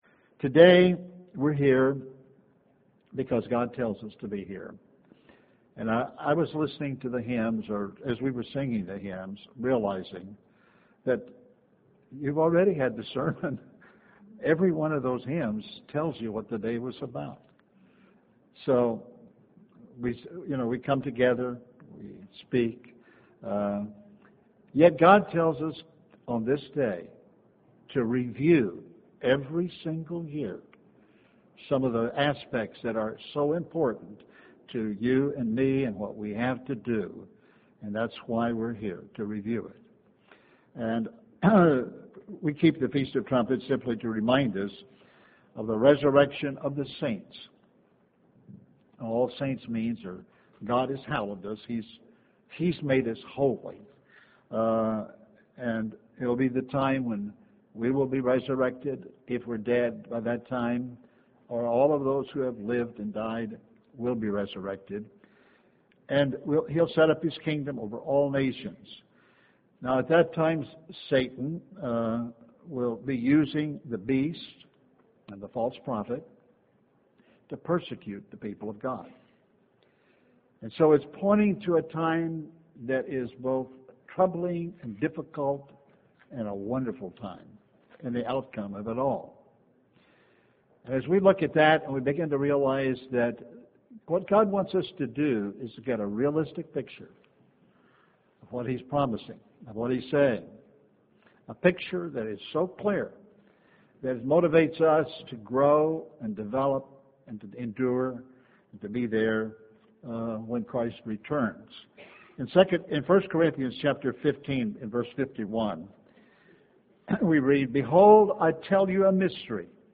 He wants us to become quality people – truly wanting to be like Christ, in the habit of going to God when we are weak, choosing good over evil. This message was given on the Feast of Trumpets.